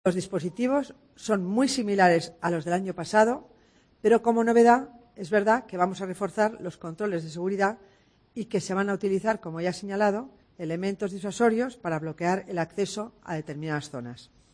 Escucha a la delegada del Gobierno en Madrid, Concepción Dancausa